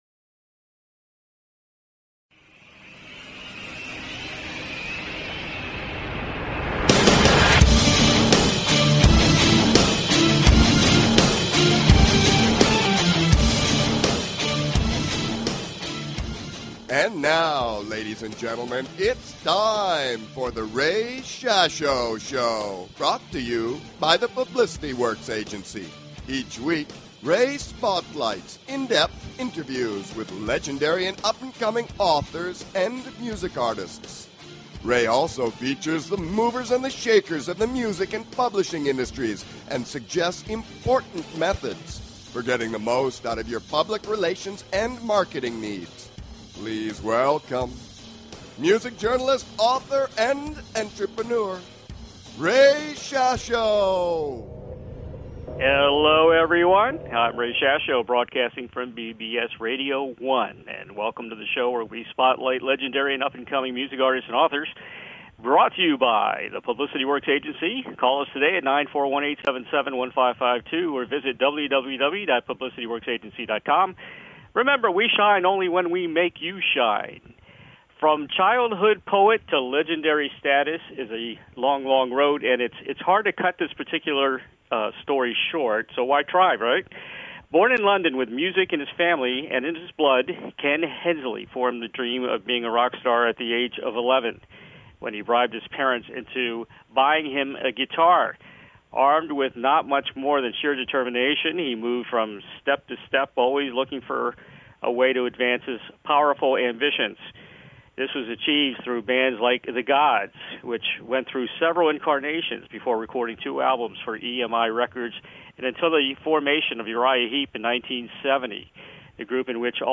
Guest, Ken Hensley